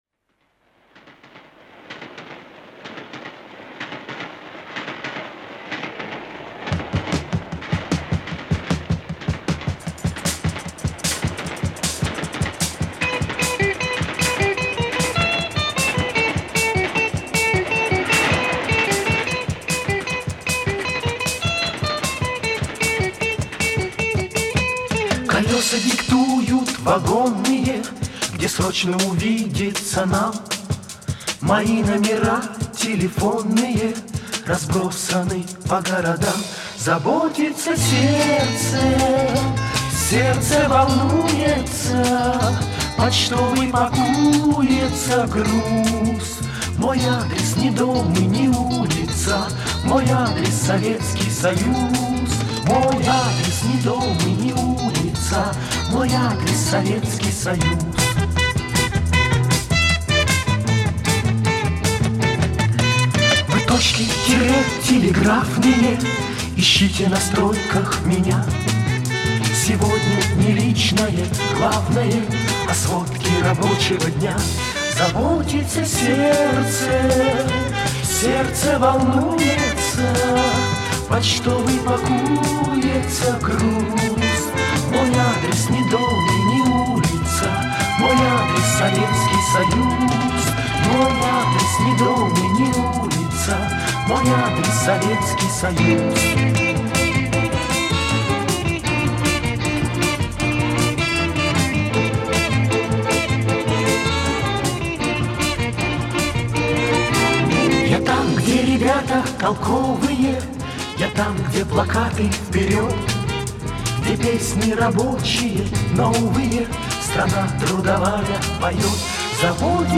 • Категория:Советские песни